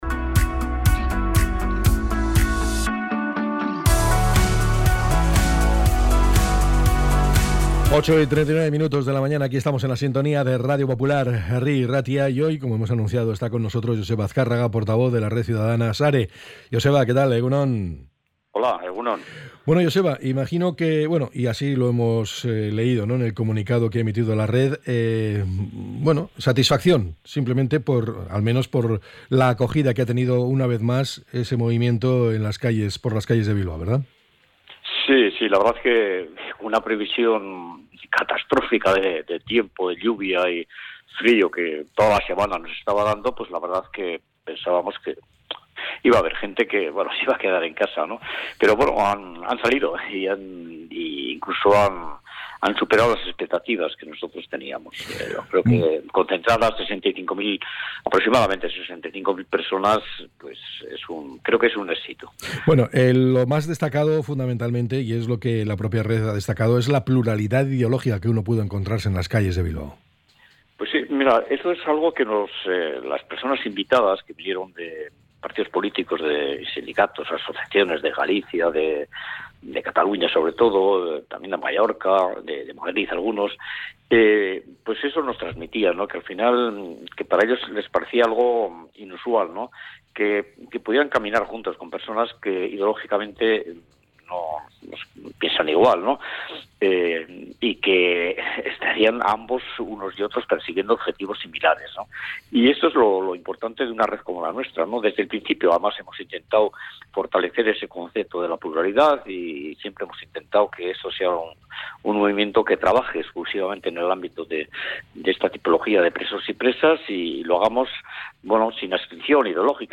ENTREV.-JOSEBA-AZKARRAGA.mp3